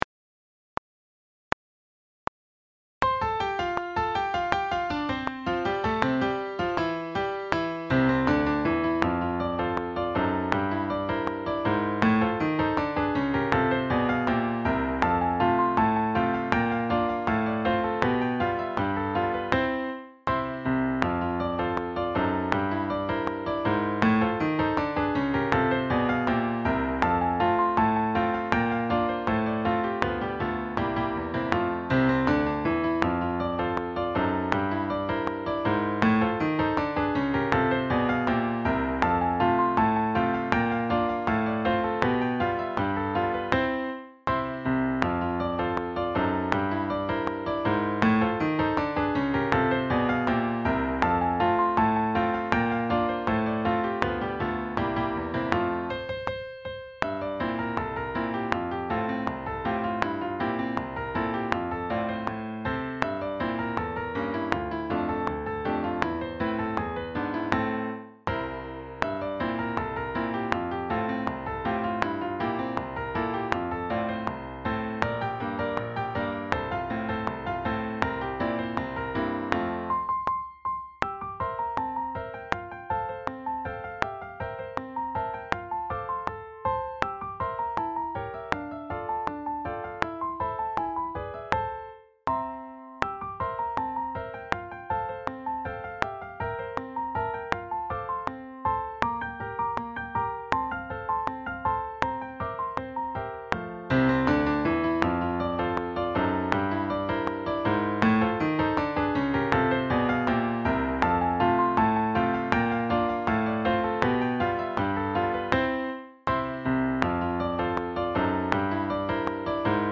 Sax Sextets
sSATBbDuration:
Backing track